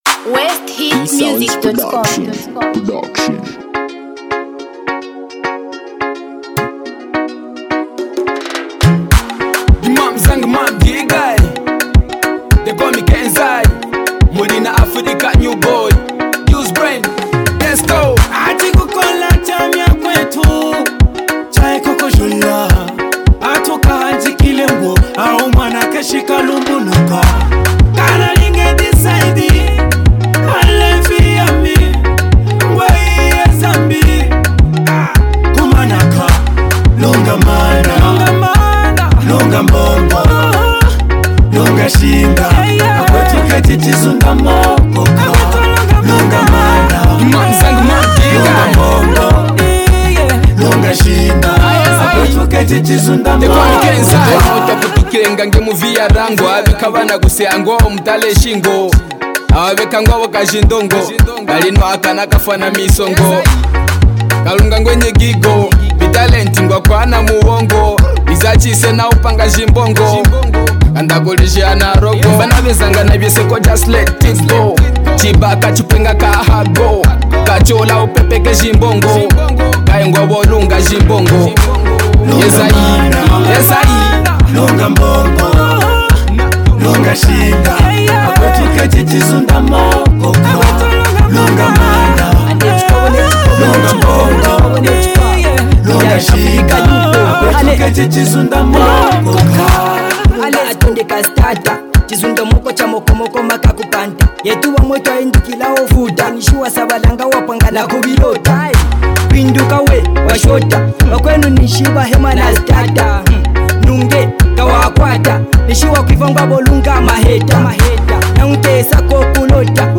Genre: Afrobeat